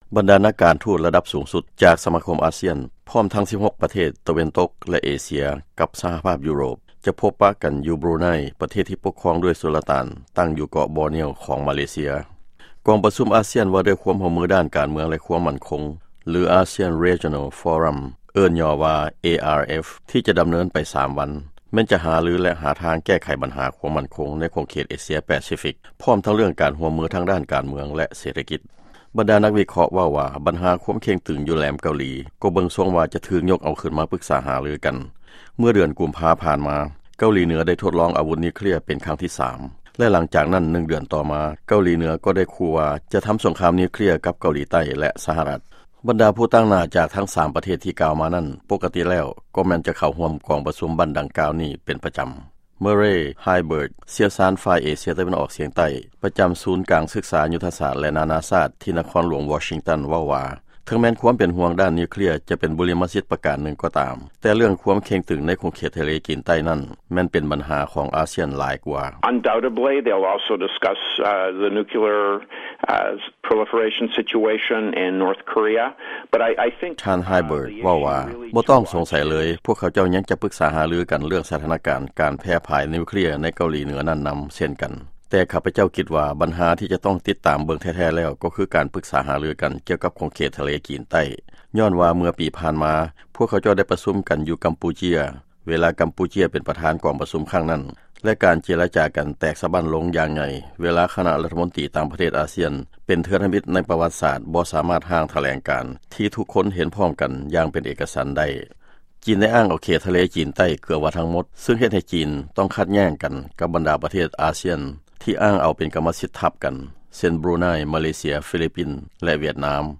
ຟັງລາຍງາຍ ການປະຊຸມຂອງ ASEAN Regional Forum